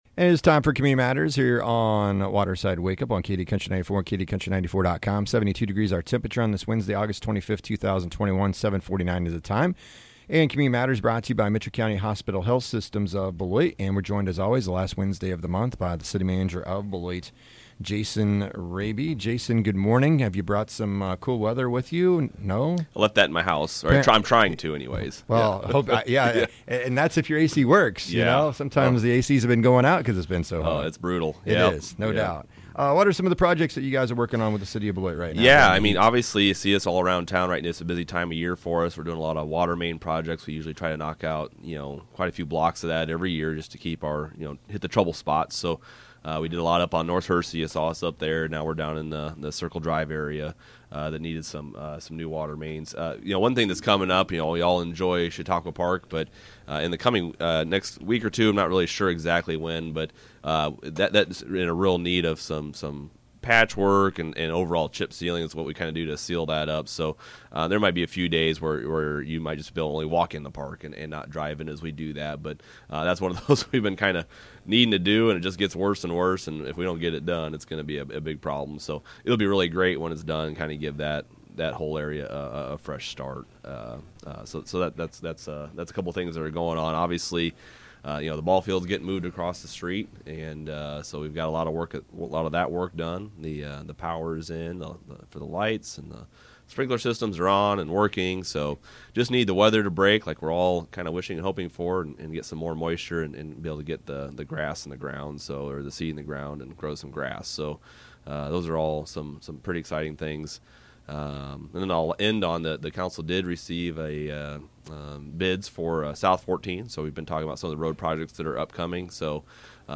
8-25-21 JASON RABE-CITY MANAGER OF BELOIT